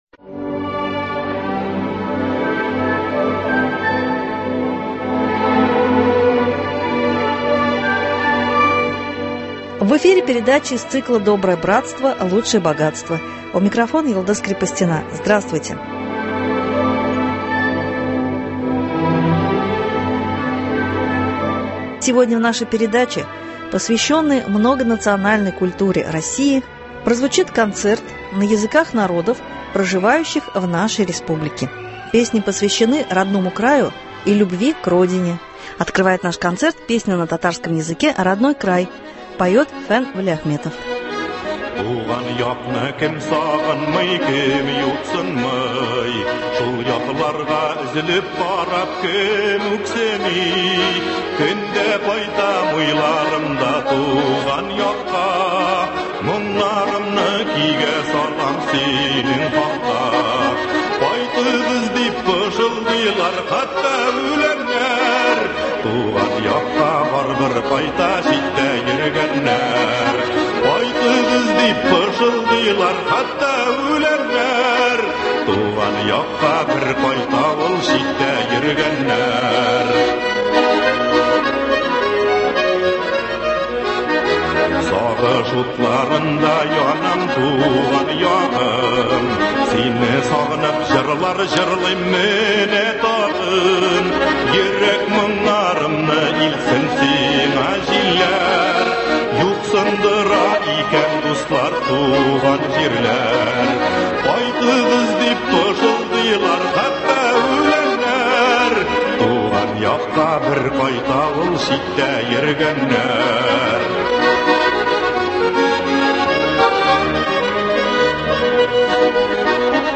Звучат песни на языках народов Татарстана.
Концерт (07.01.23)